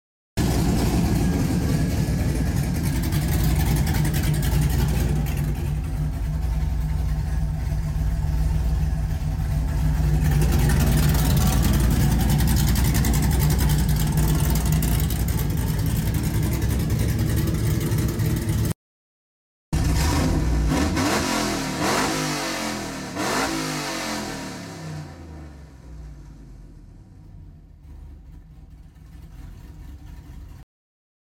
Swapped a 6.0 in place of the 5.3 and she’s rowdy now